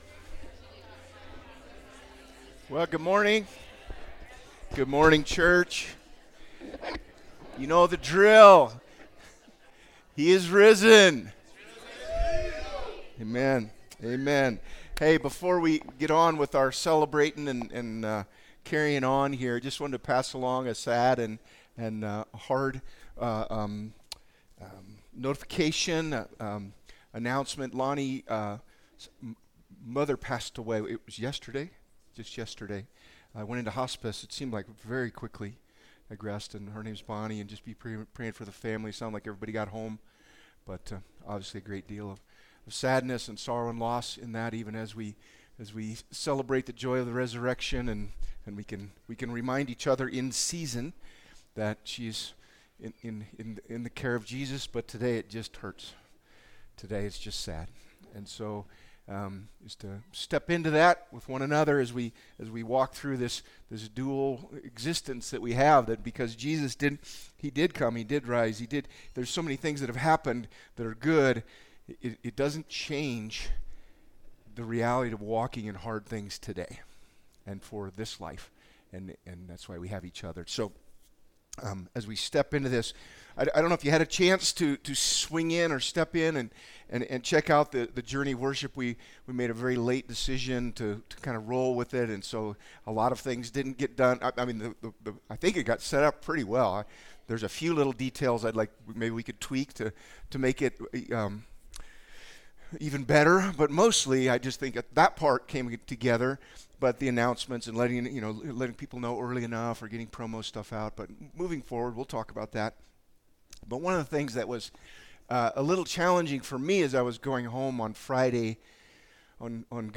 Service Type: Sunday